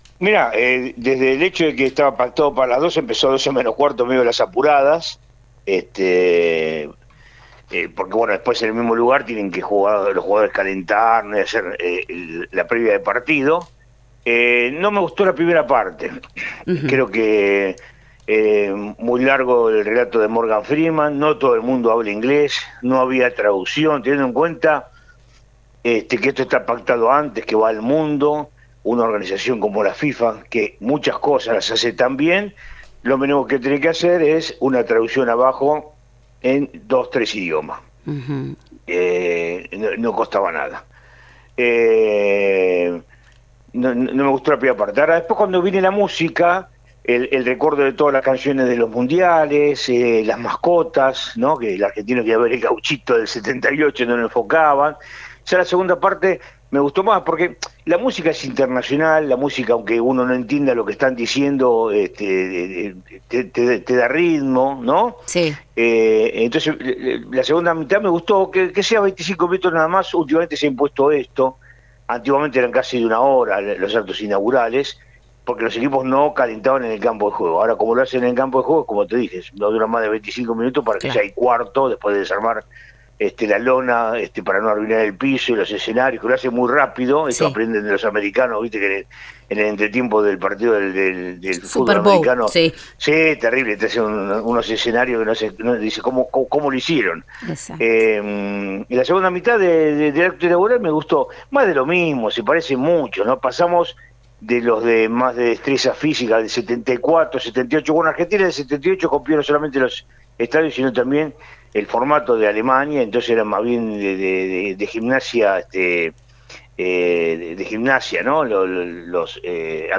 dialogó con el programa radial “Te lo dije” y dio su punto de vista sobre el capitán de la Selección Argentina.